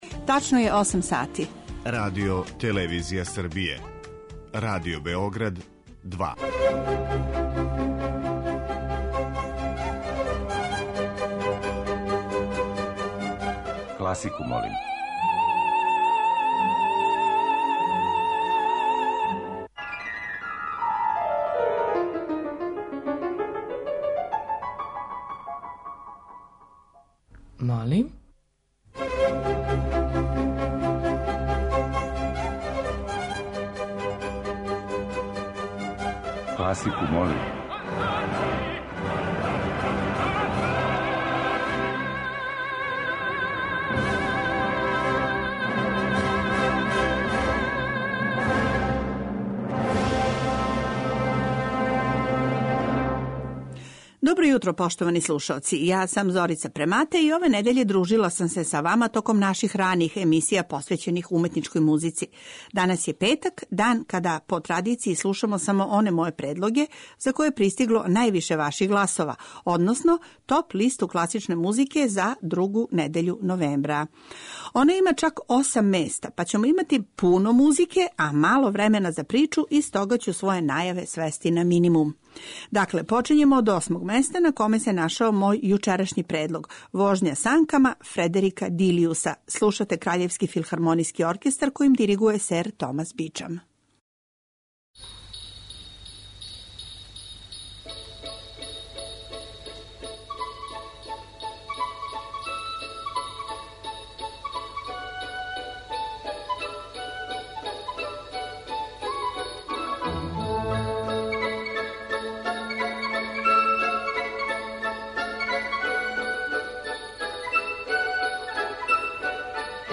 Недељна топ-листа класичне музике Радио Београда 2